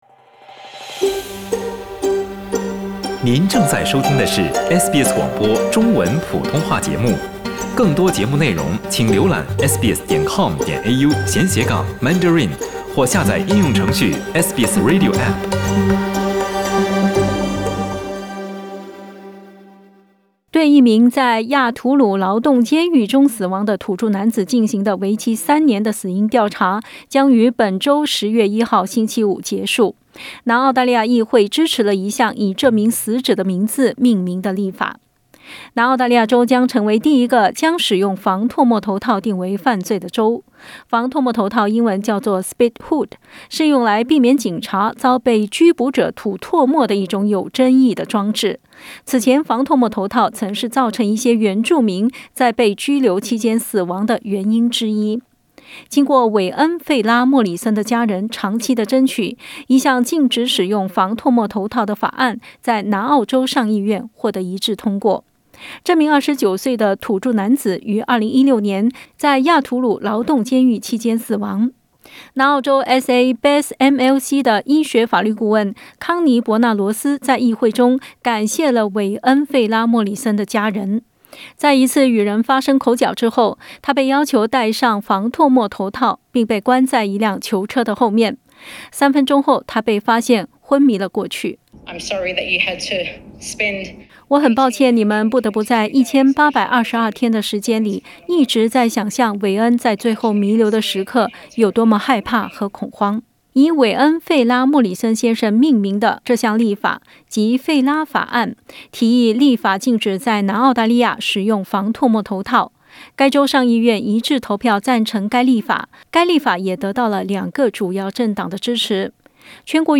一項禁止使用“防唾沫頭套”的法案在南澳州上議院穫得通過。（點擊圖片收聽詳細報道）